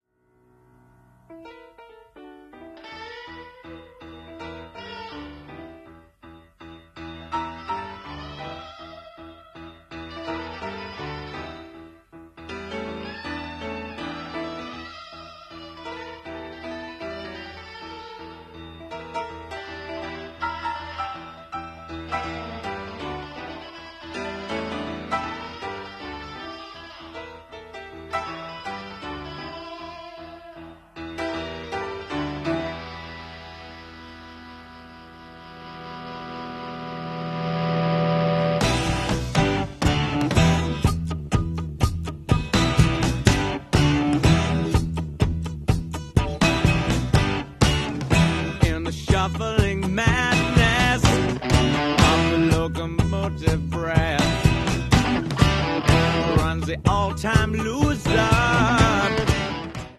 Steam locomotives at Ffesttiniog And